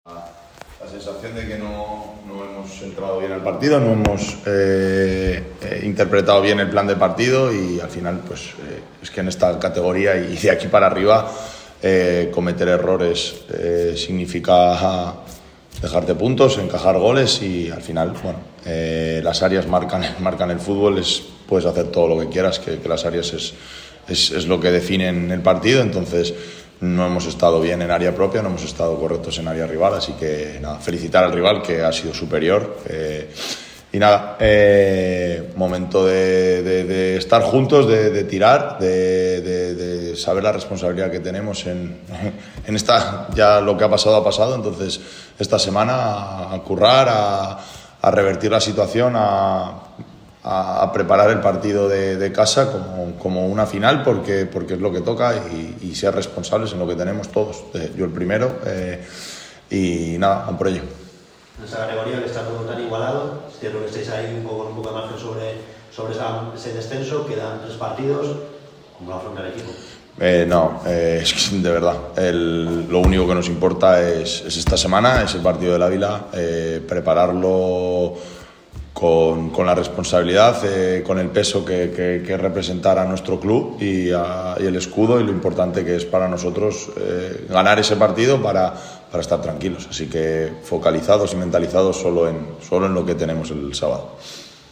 Ruedas de prensa